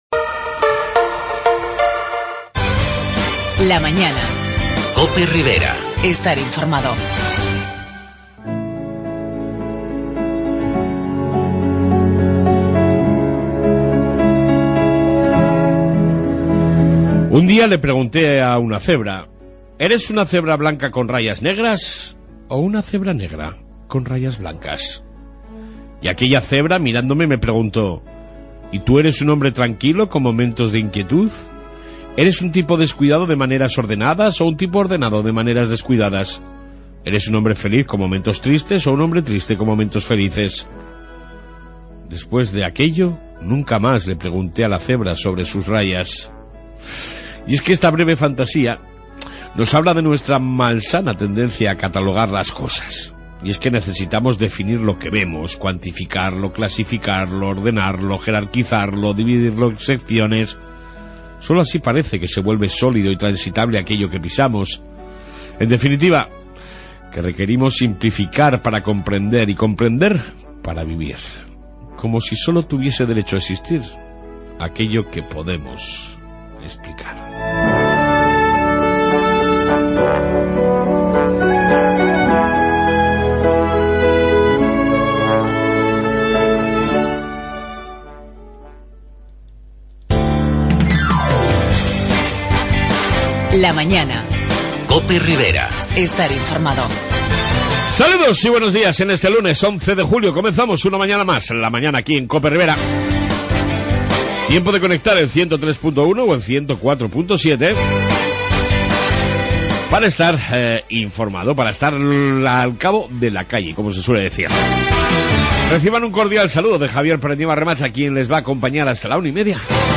AUDIO: 11/07/16 Reflexión diaria y amplia entrevista con la Alcaldesa de Cintruenigo Raquel Garbayo.